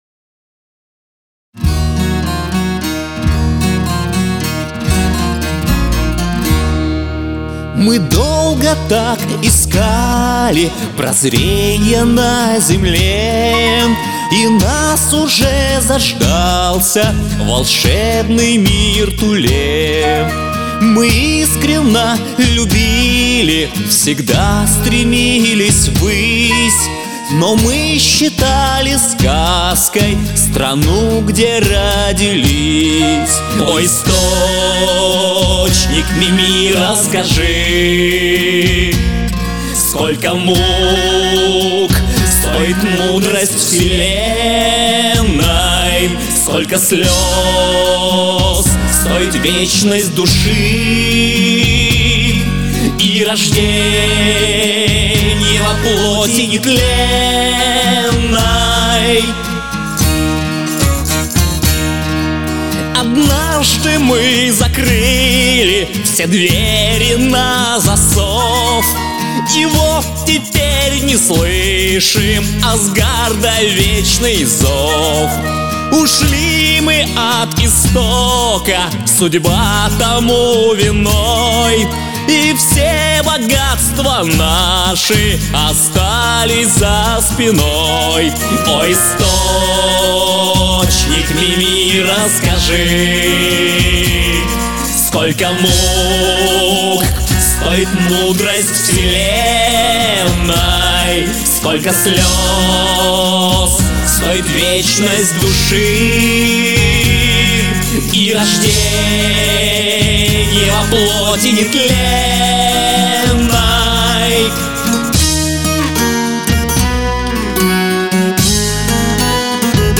песня с аранжировкой